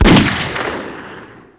gun-s.wav.wav